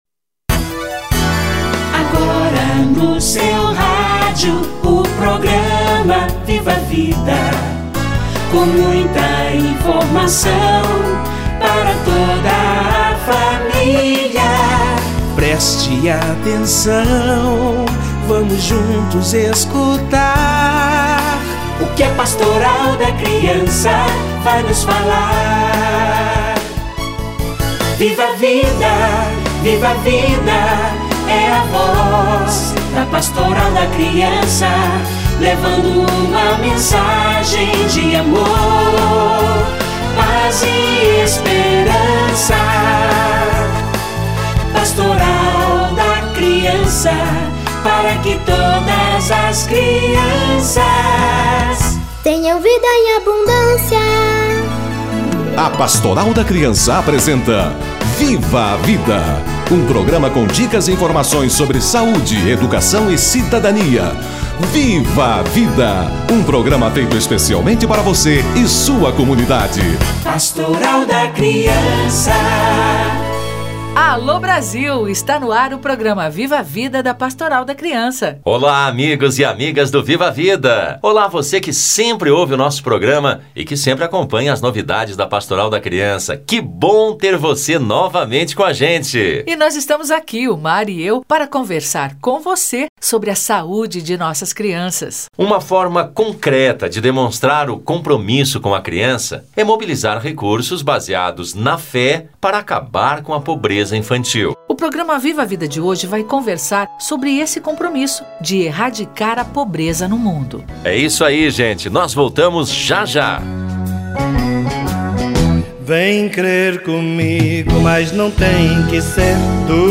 Criança e combate à pobreza - Entrevista